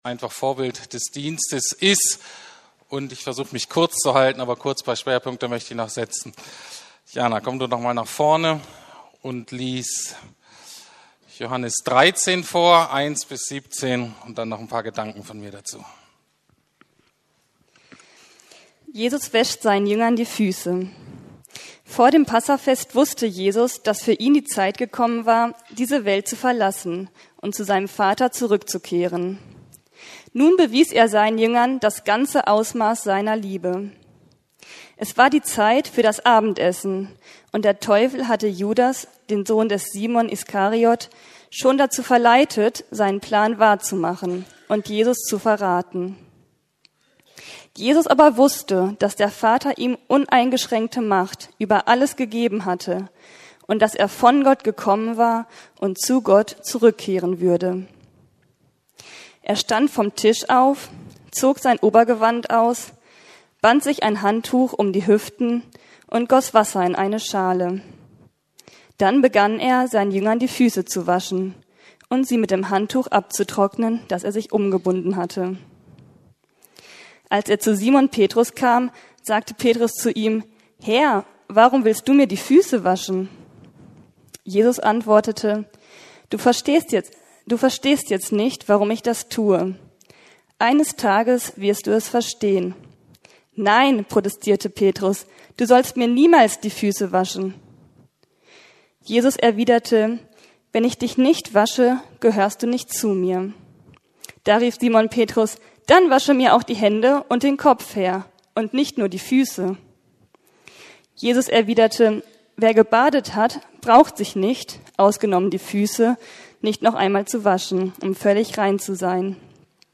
Unsere Berufung neu entdecken: Dienst/Diakonie ~ Predigten der LUKAS GEMEINDE Podcast